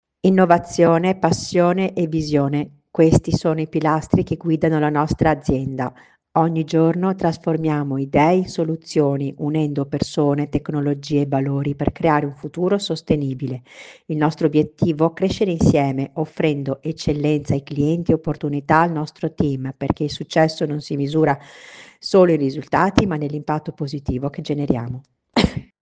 Gli speaker non sono tutti uguali. Ci sono quelli che ci provano, e i veri professionisti.
Speaker che ci prova